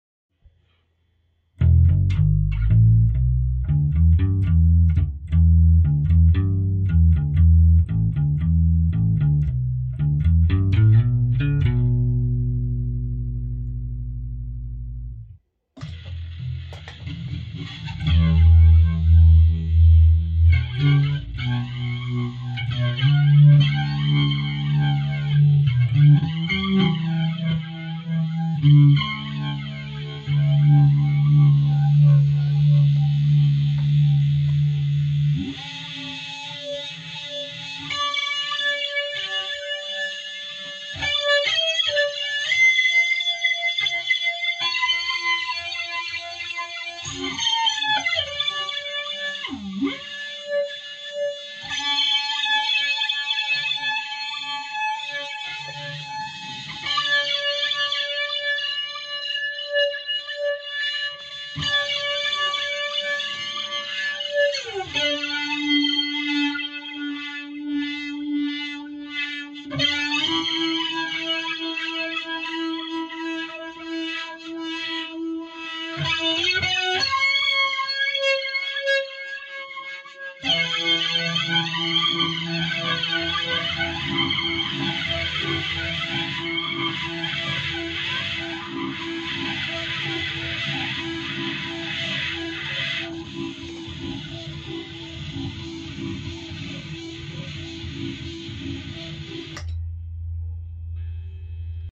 se agrego el DD-200 de boss y el fuzz después en lugar de antes.